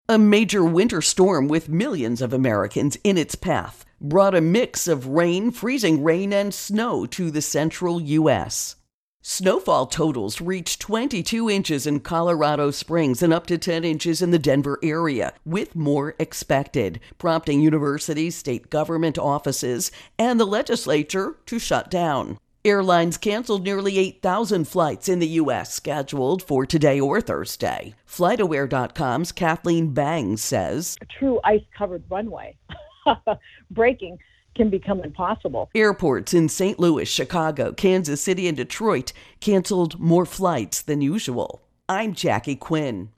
Winter Weather canceled Intro and Voicer